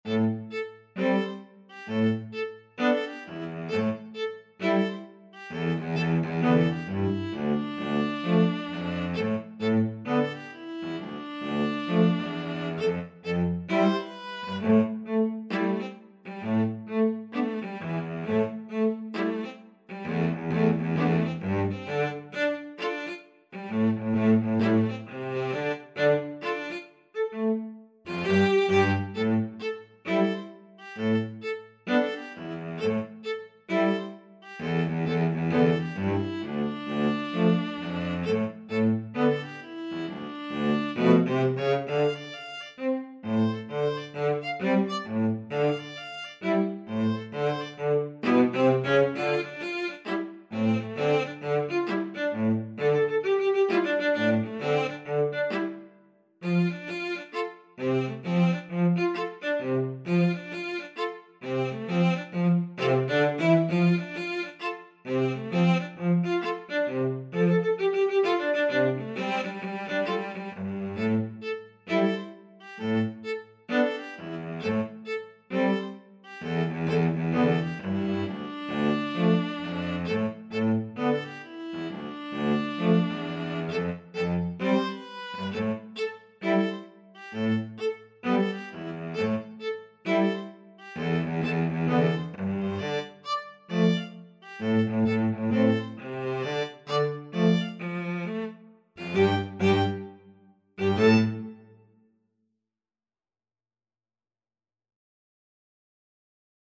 ViolaCello 1Cello 2
A funky hop-hop style piece.
String trio  (View more Intermediate String trio Music)
Pop (View more Pop String trio Music)